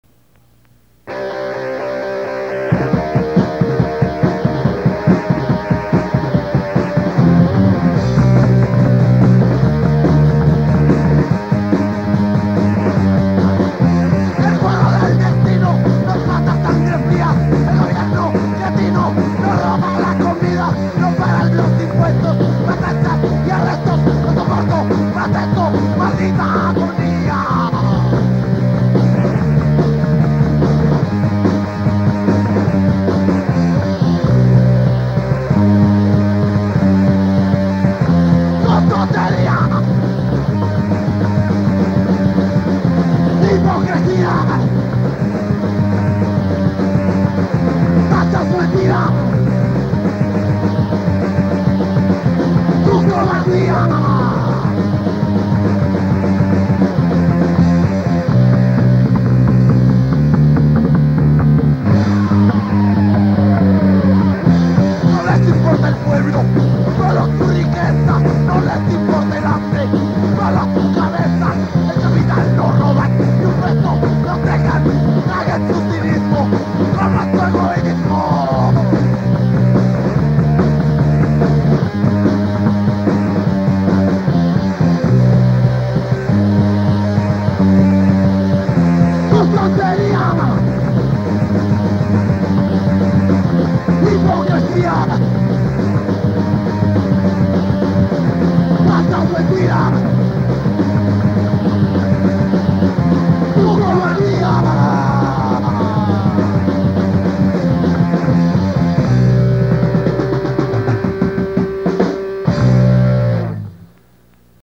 con 4 acordes muy pegajosos